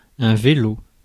Ääntäminen
France: IPA: [œ̃ ve.lo]